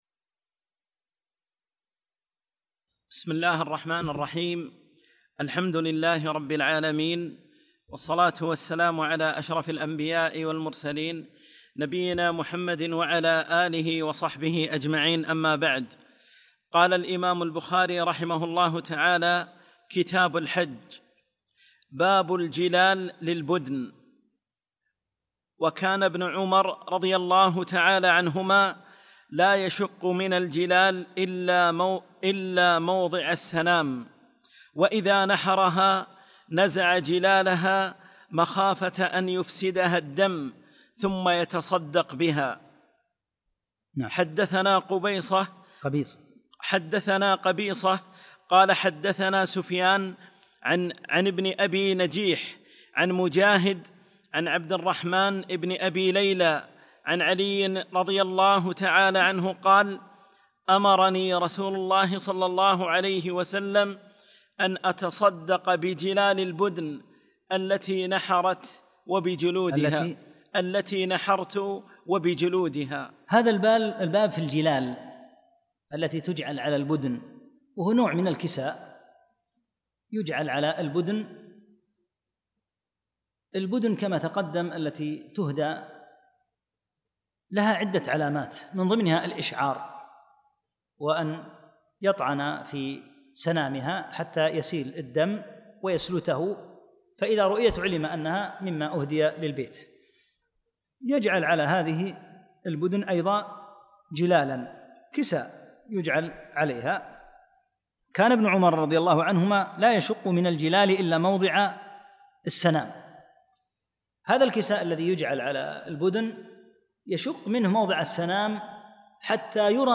18- الدرس الثامن عشر